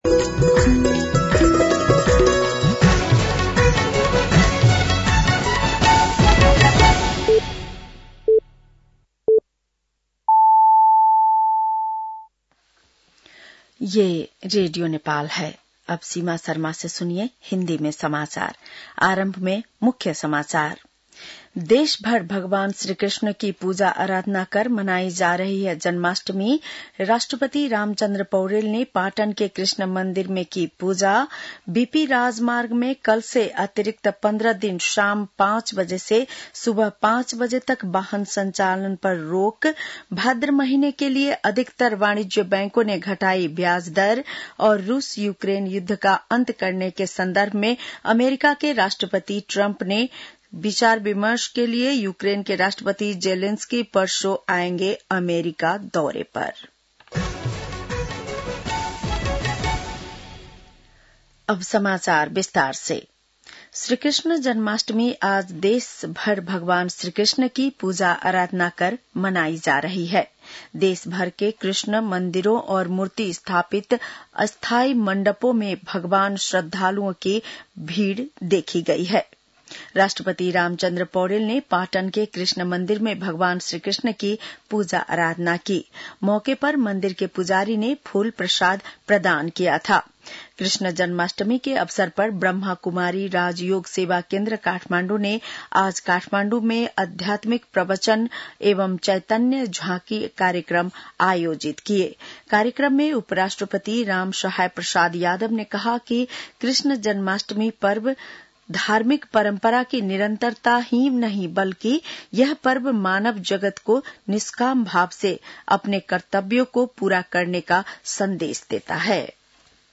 बेलुकी १० बजेको हिन्दी समाचार : ३१ साउन , २०८२